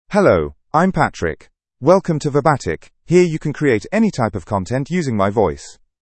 Patrick — Male English (United Kingdom) AI Voice | TTS, Voice Cloning & Video | Verbatik AI
Patrick is a male AI voice for English (United Kingdom).
Voice sample
Male
Patrick delivers clear pronunciation with authentic United Kingdom English intonation, making your content sound professionally produced.